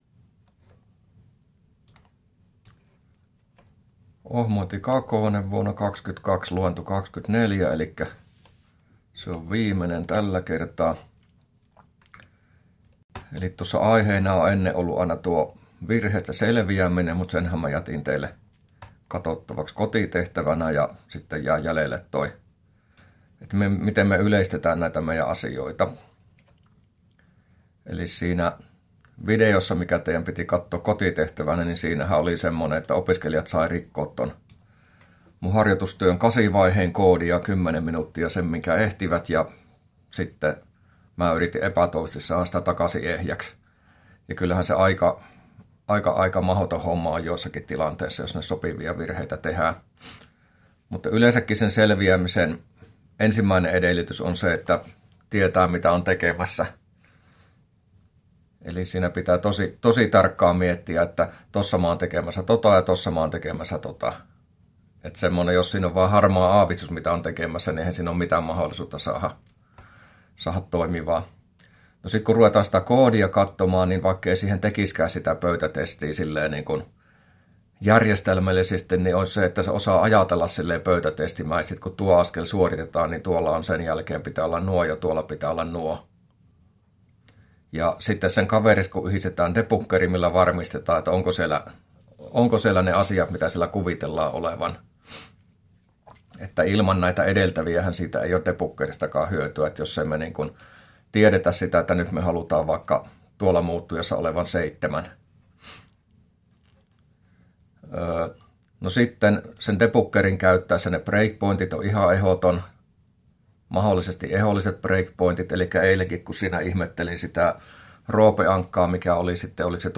luento24a